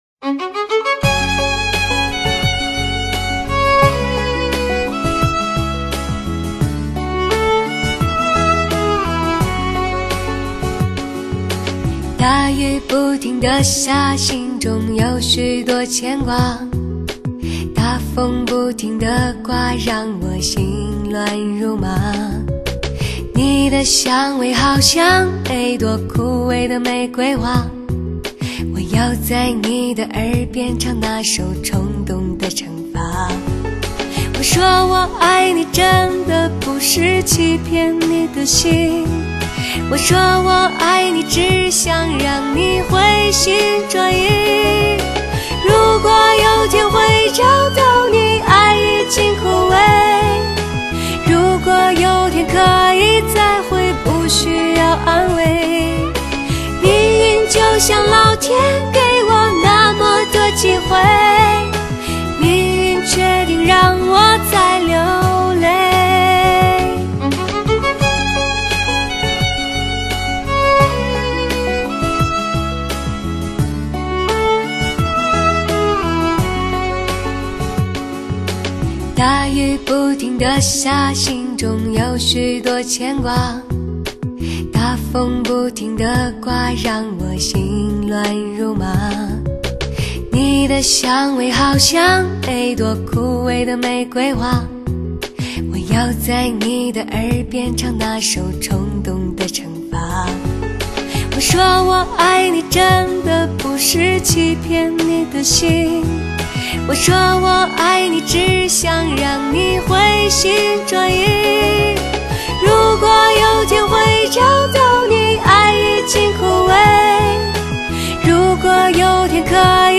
专辑类型：DSD发烧人声